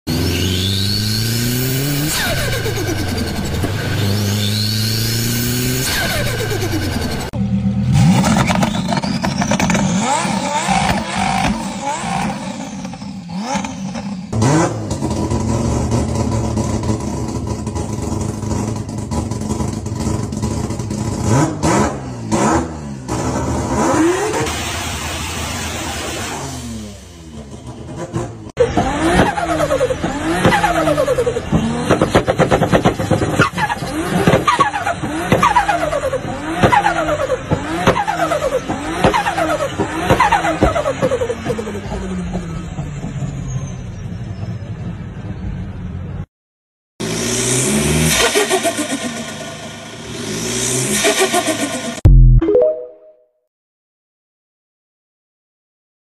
Top 5 Craziest Turbo Sounds! sound effects free download